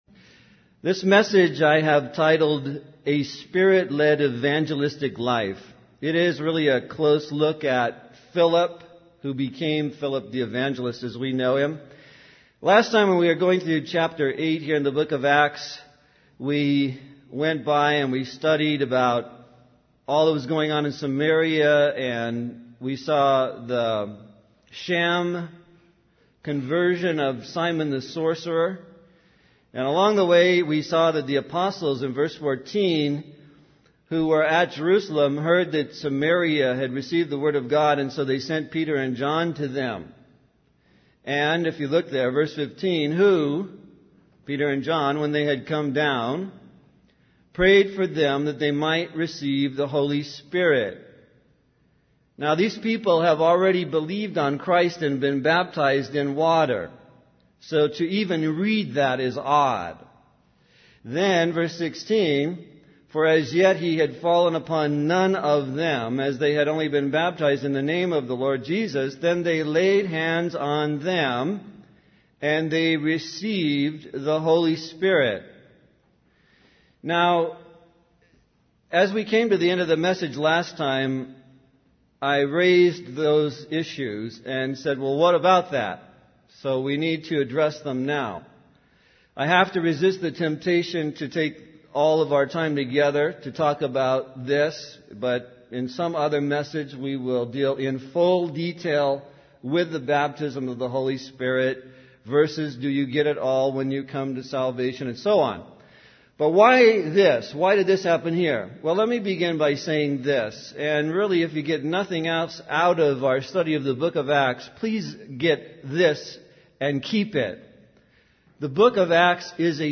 In this sermon titled 'A Spirit Led Evangelistic Life,' the speaker focuses on the story of Philip the Evangelist in the book of Acts. The sermon begins by discussing the events in Samaria, including the sham conversion of Simon the Sorcerer.